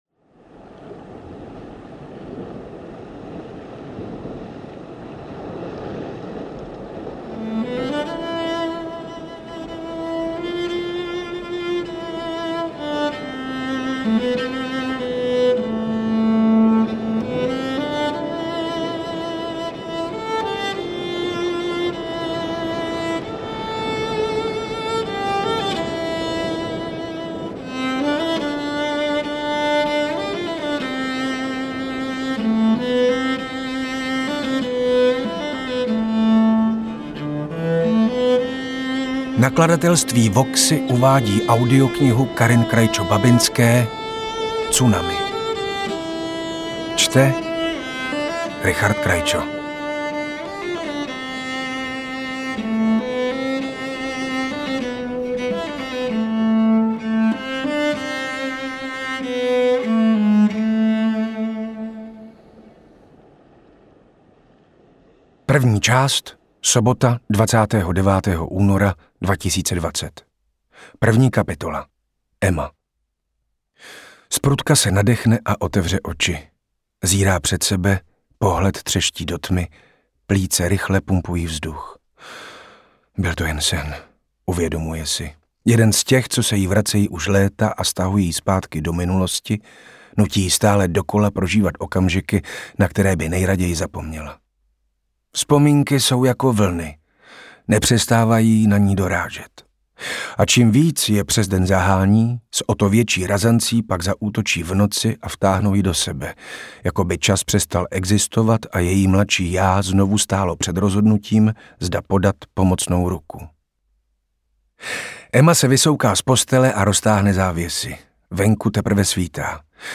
Interpret:  Richard Krajčo
Také výborně načtený Krajčem, což mi přijde strašně fajn, že jí čte její manžel :o) Autorku jsem doteď neznala a dávám si předsevzetí, že letos se budu více věnovat našim autorkám a autorům.
AudioKniha ke stažení, 16 x mp3, délka 10 hod. 10 min., velikost 556,4 MB, česky